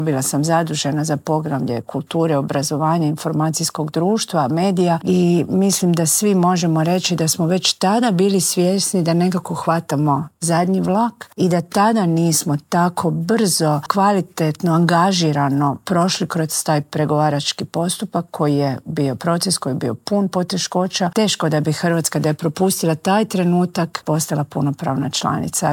Ministrica kulture i medija Nina Obuljen Koržinek u Intervjuu tjedna Media servisa osvrnula se na proteklih 12 godina i poručila da možemo biti ponosni:
Inače, ministrica se prisjetila izazovnih vremena kada je i sama bila članica pregovaračkog tima za pristupanje EU: